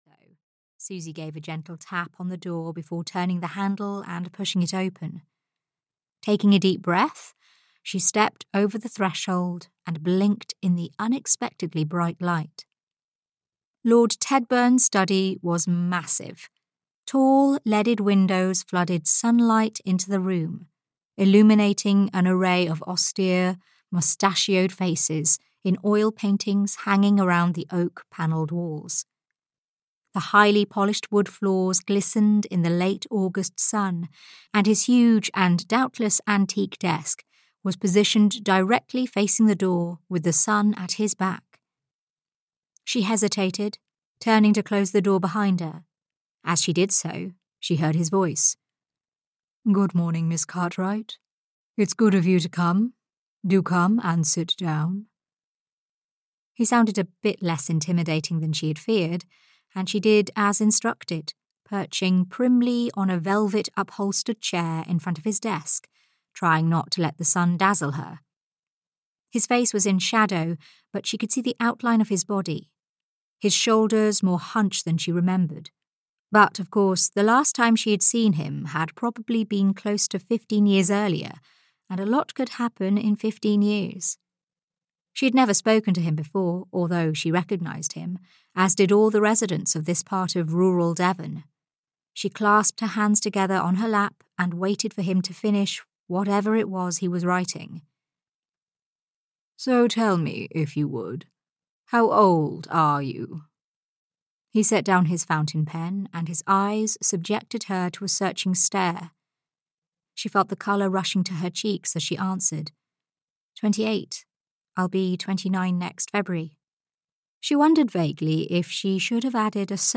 Dreaming of Verona (EN) audiokniha
Ukázka z knihy